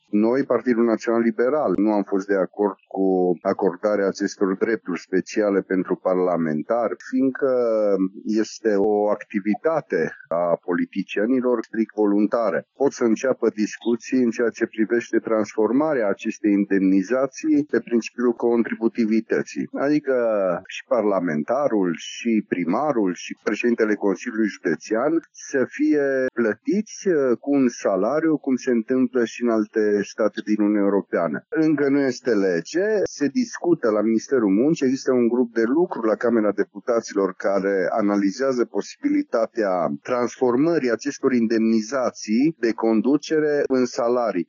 Senatorul PNL Cristian Chirteș crede că această inițiativă a foștilor colegi e legală, însă nu și morală, și susține că la această oră România nu își permite să acorde aceste drepturi speciale.